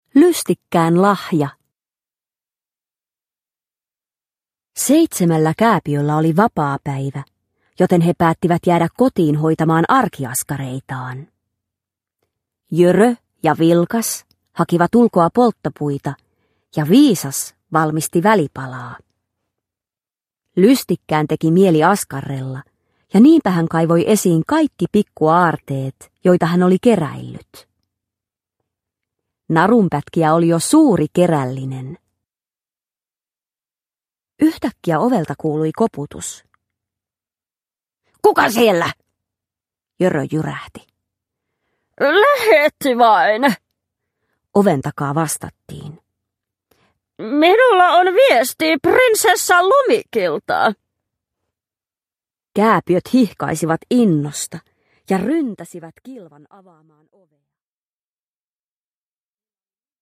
Lystikkään lahja – Ljudbok – Laddas ner